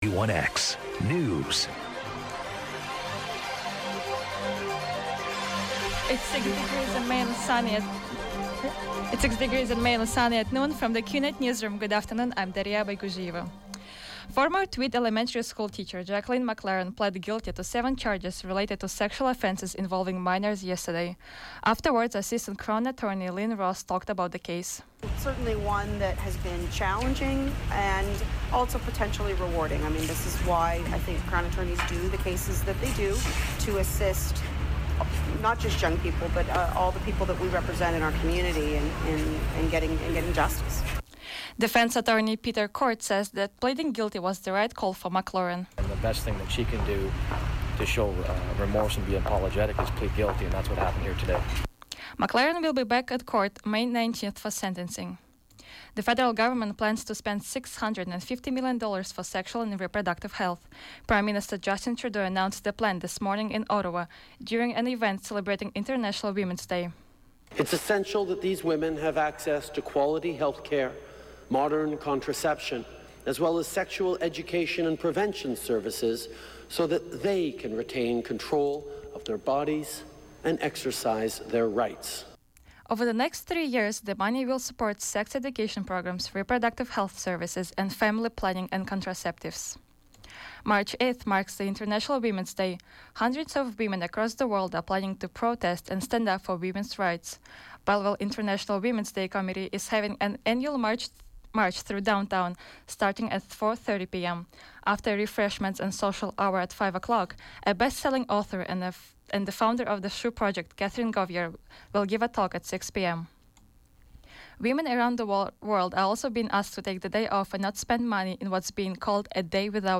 91X FM Newscast- Wednesday, March 8, 2017, noon